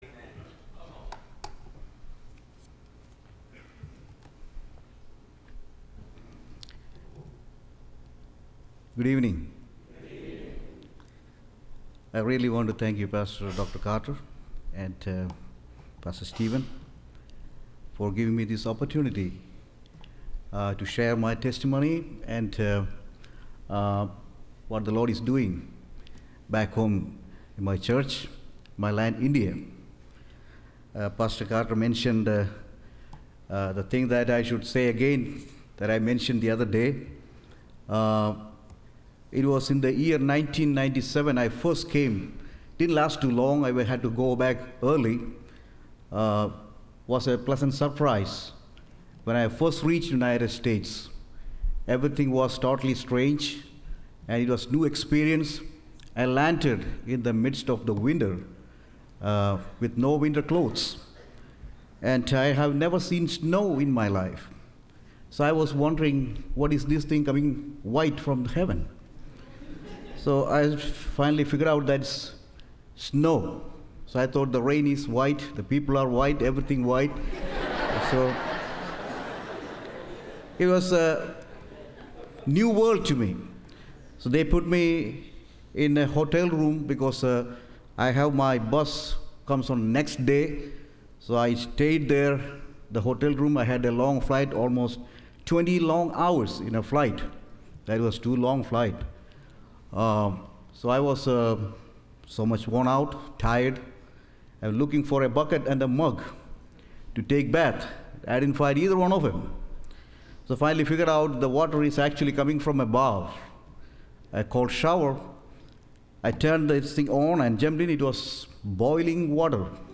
Missionary Testimony – Landmark Baptist Church
Service Type: Wednesday